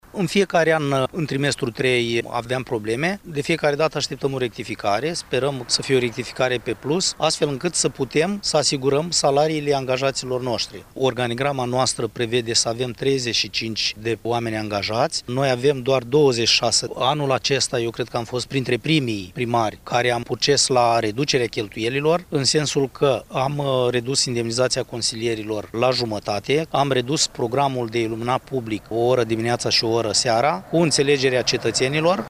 Situația este la fel de gravă și în comuna Ivănești, unde primarul Vasile Novac spune ca deja a înjumătățit îndemnizațiile consilierilor locali iar funcționarea iluminatului public a fost restricționată: